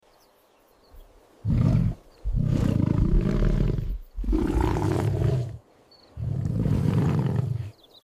Lion Roar Powerful Ultra Realistic Téléchargement d'Effet Sonore
Lion Roar Powerful Ultra Realistic Bouton sonore